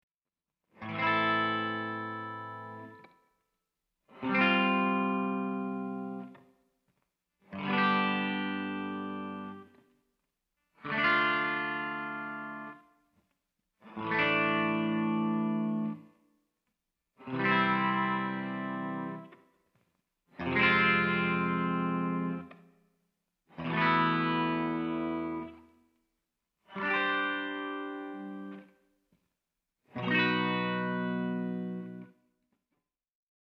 FIGURE 2b illustrates a sequence of randomly chosen major, minor and suspended chords.